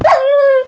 minecraft / sounds / mob / wolf / death.ogg
death.ogg